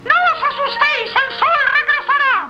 Worms speechbanks
incoming.wav